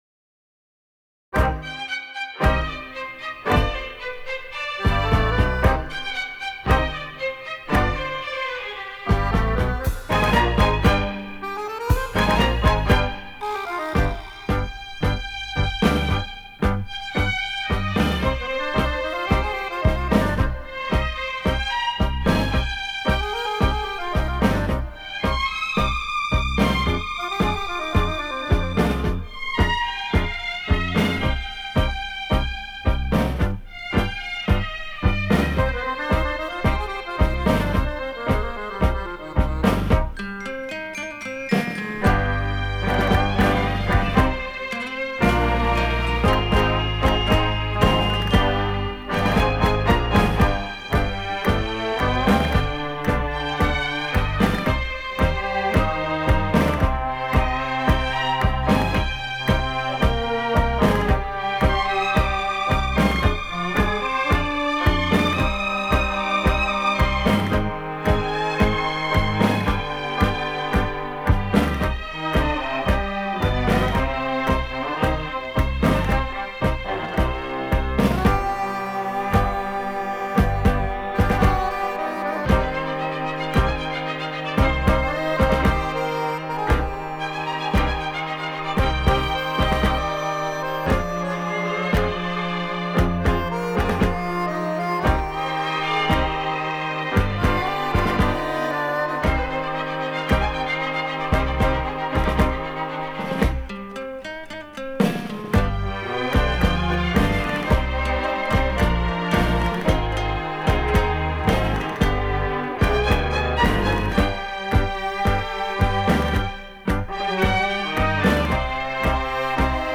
旋律优美，节奏欢快。
喜欢节奏鲜明的探戈舞曲，谢谢分享